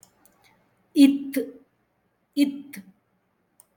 ith